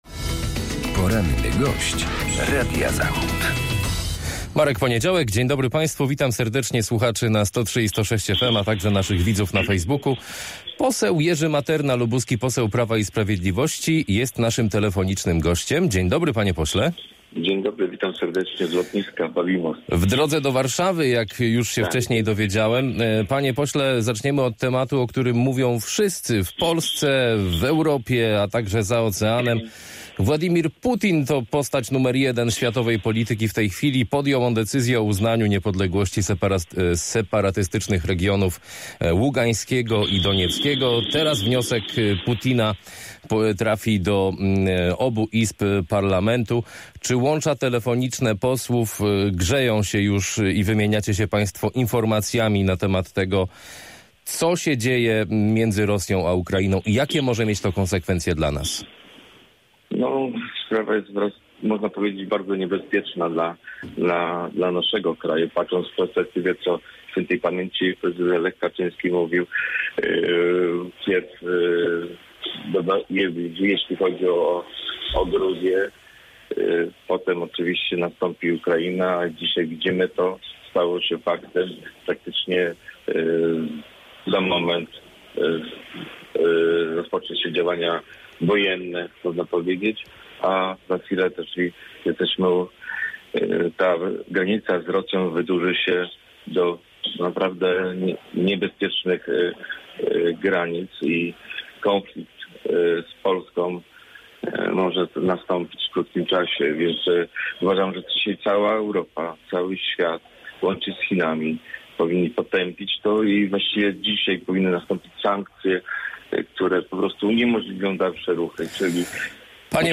Z posłem Prawa i Sprawiedliwości rozmawiał